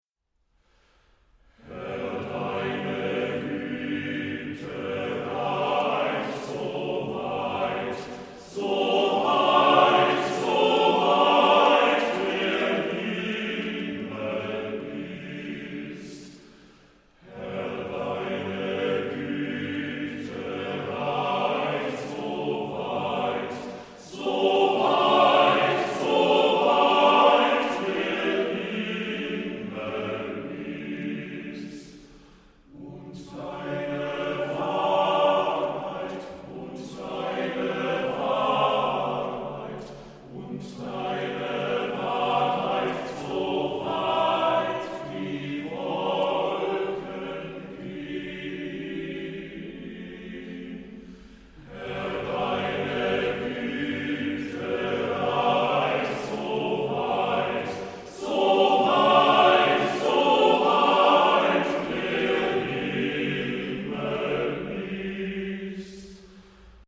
DER MÄNNERCHOR